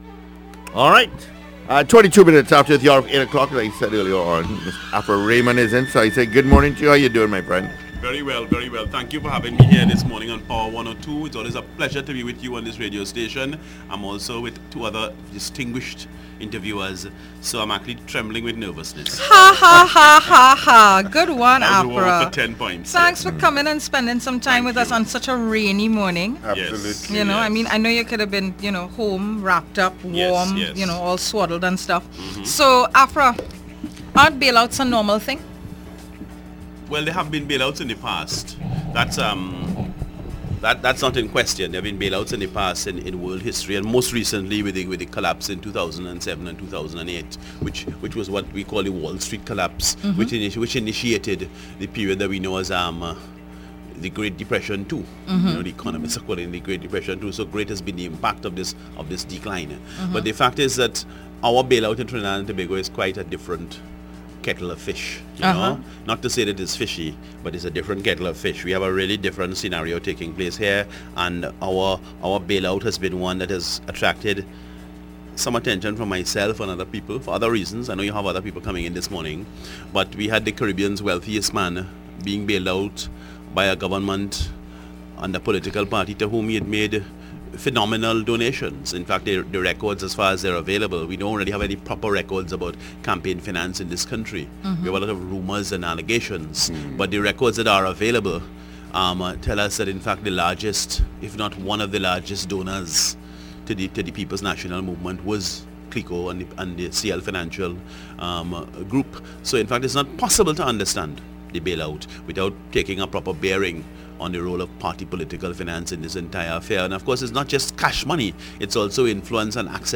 AUDIO: The Power Breakfast Show interview on 102.1 FM – 1 June 2016